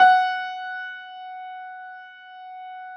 53h-pno16-F3.wav